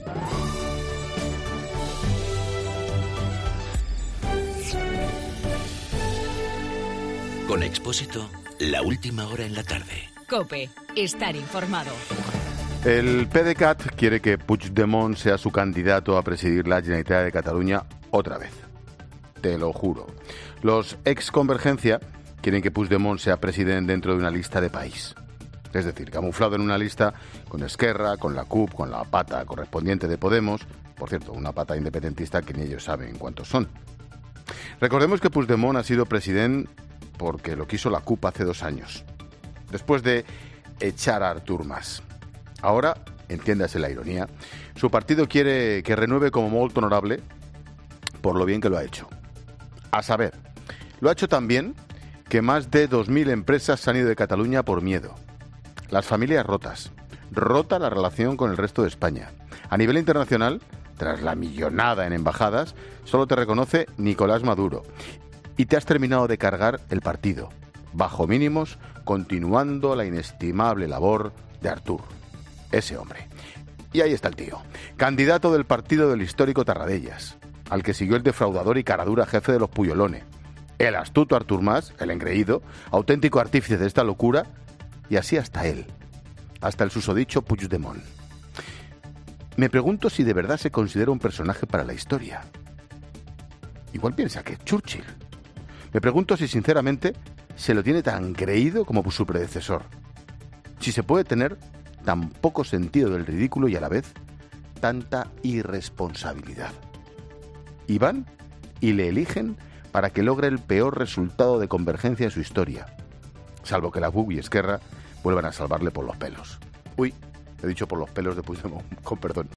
Monólogo de Expósito
El comentario de Ángel Expósito por la candidatura de Puigdemont en el PDeCAT.